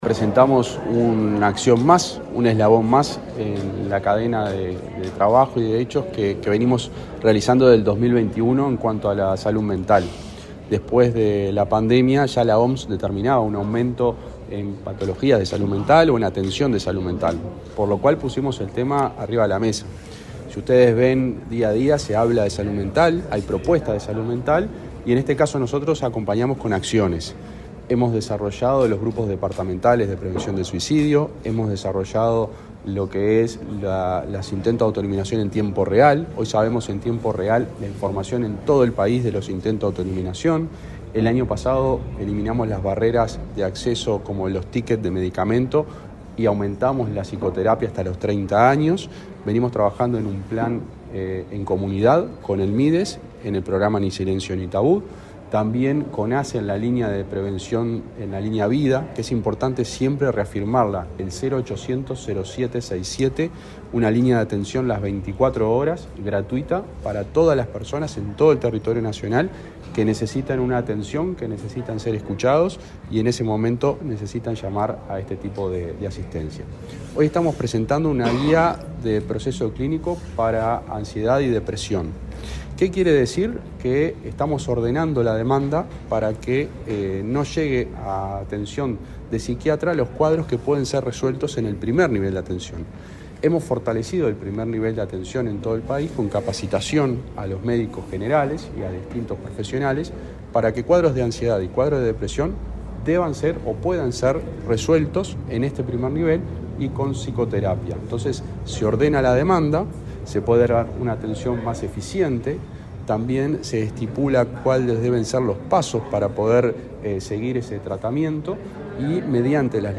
Declaraciones del subsecretario de Salud Pública, José Luis Satdjian
Declaraciones del subsecretario de Salud Pública, José Luis Satdjian 27/08/2024 Compartir Facebook X Copiar enlace WhatsApp LinkedIn El subsecretario del Ministerio de Salud Pública, José Luis Satdjian, participó, este martes 27 en la sede de esa cartera, en el lanzamiento de una guía de recomendaciones para el proceso asistencial de personas con ansiedad y depresión. Luego dialogó con la prensa.